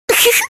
Giggle Sfx